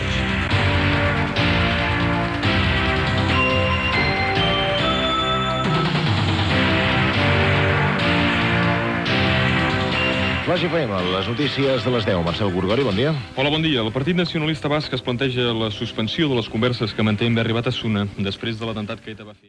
Careta i primers segons de l'informatiu sobre el Partit Nacionalista Basc.
Informatiu